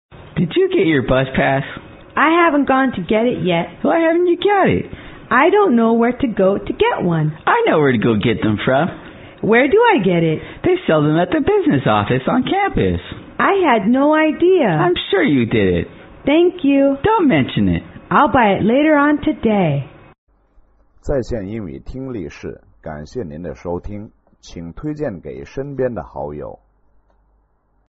乘车英语对话-Where to Buy a Pass(2) 听力文件下载—在线英语听力室